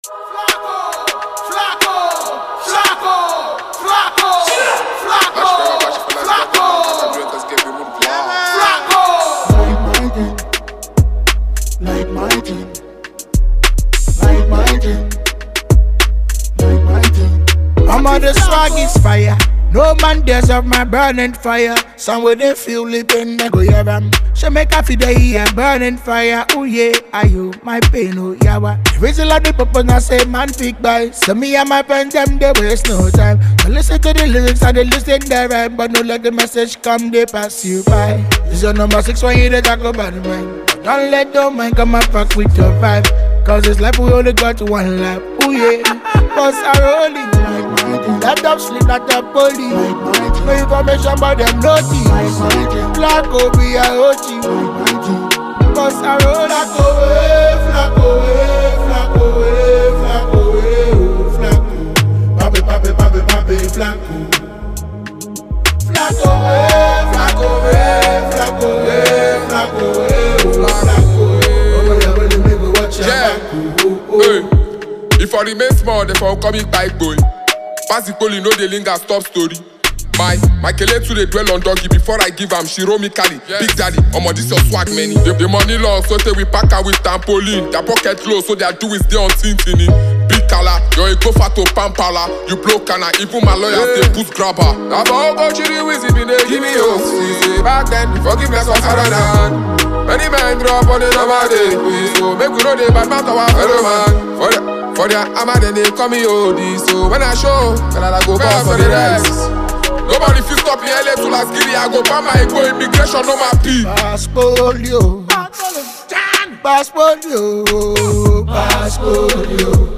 intense song
vocalist